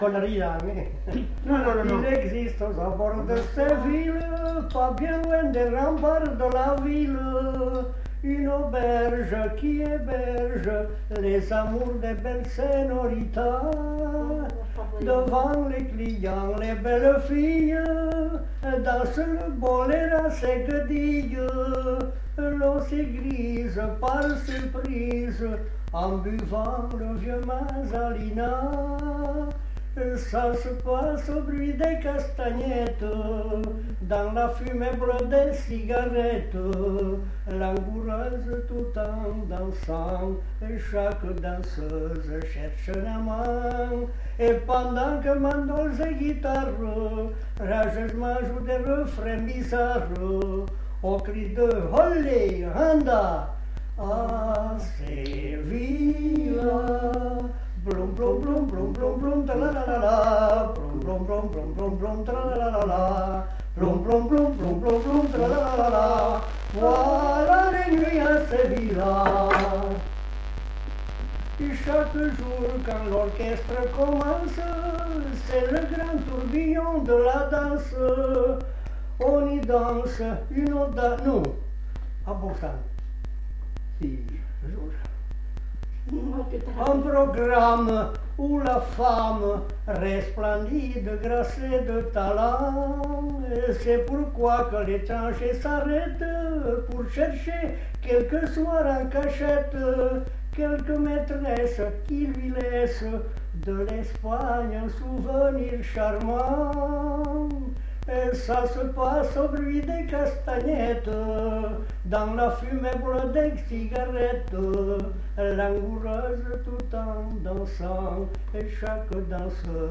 Genre : chant
Effectif : 1
Type de voix : voix d'homme
Production du son : chanté
Danse : valse